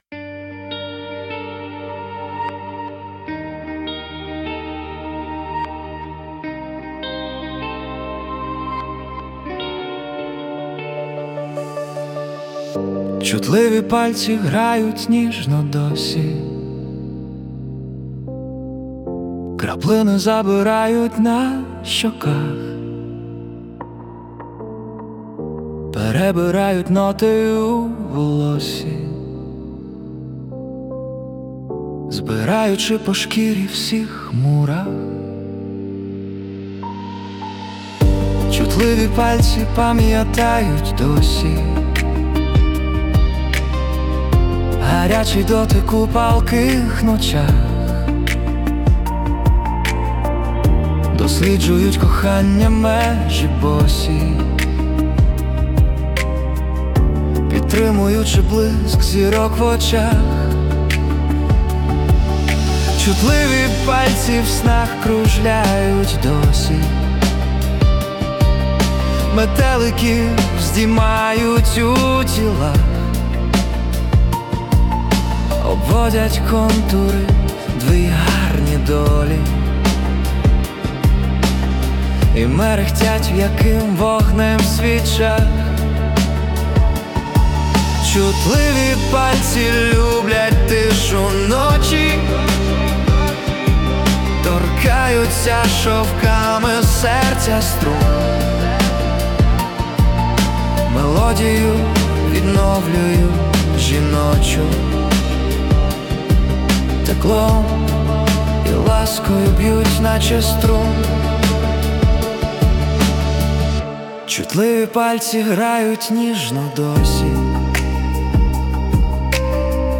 Музика і вокал ШІ - SUNO AI
СТИЛЬОВІ ЖАНРИ: Ліричний
ВИД ТВОРУ: Пісня